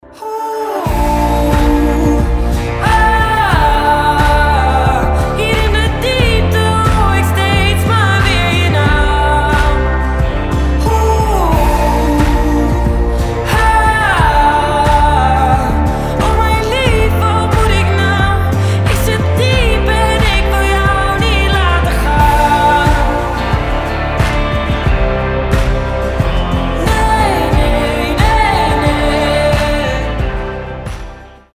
атмосферные
женский голос